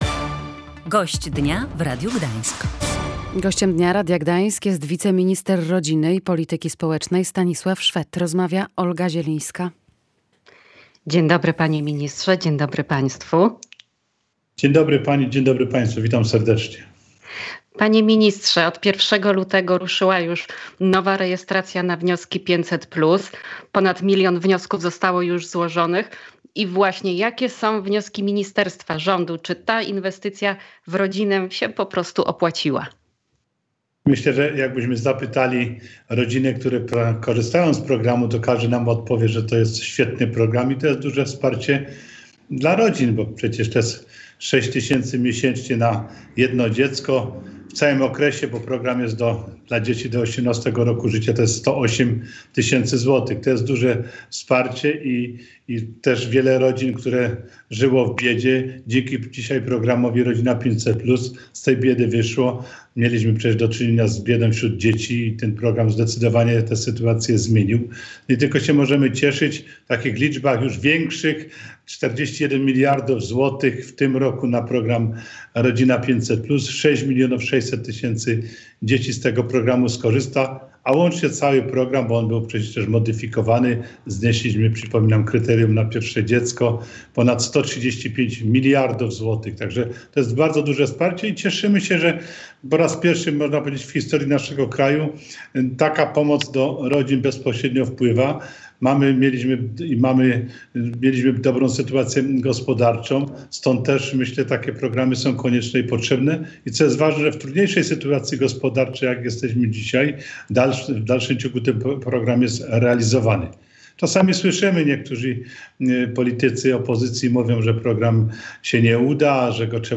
Ale to nie koniec pomocy – mówił w Radiu Gdańsk wiceminister Stanisław Szwed.